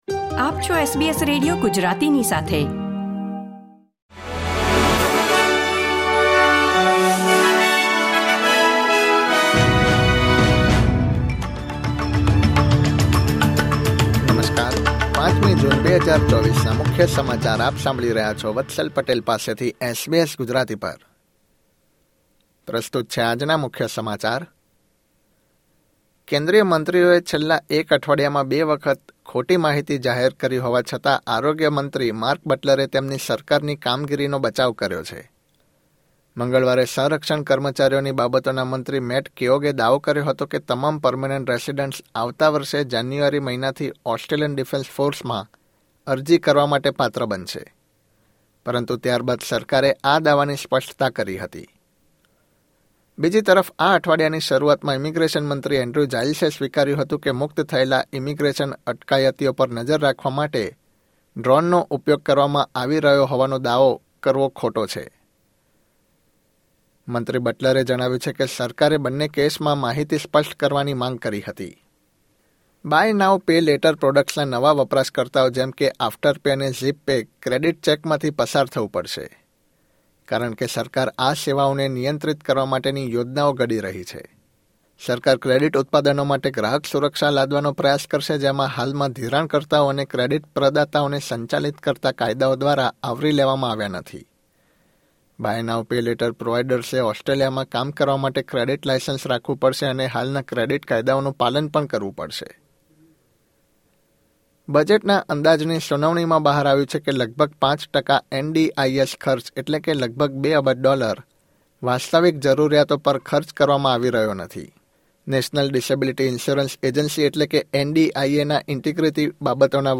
SBS Gujarati News Bulletin 5 June 2024